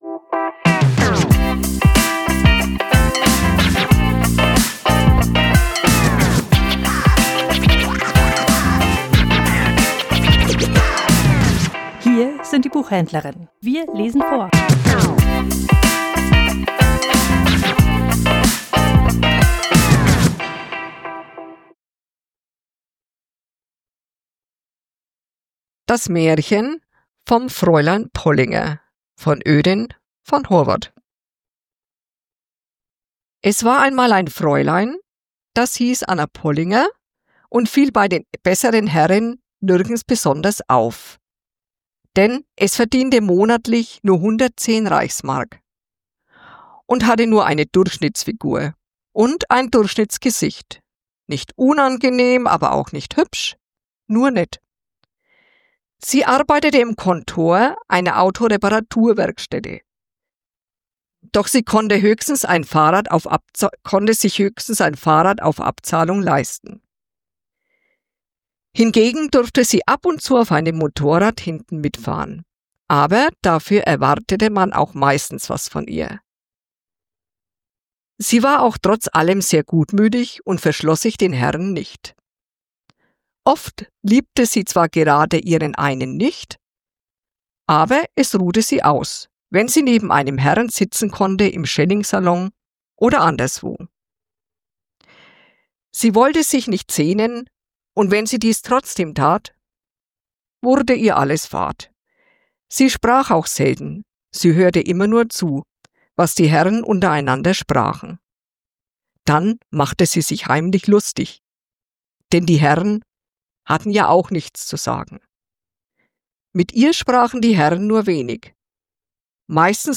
Vorgelesen: Das Märchen vom Fräulein Pollinger